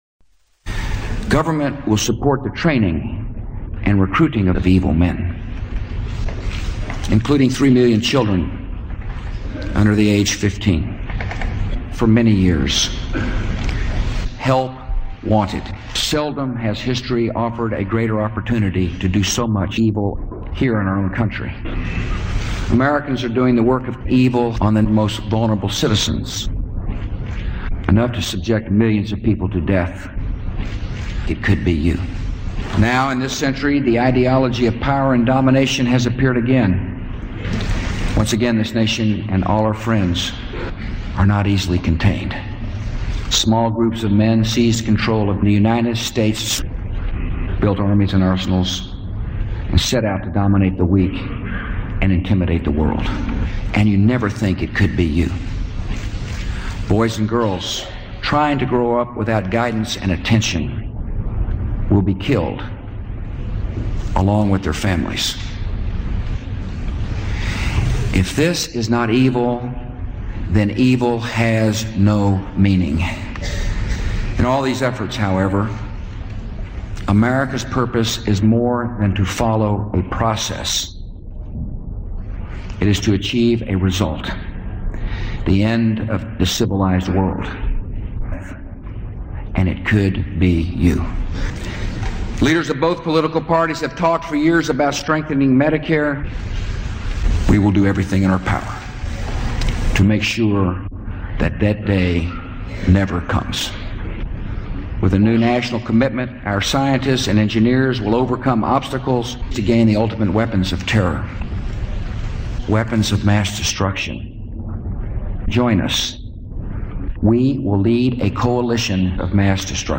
You may also hear the occasional cuss word in some translations: you have been warned.
Source Material: State of the Union Address, January 28, 2003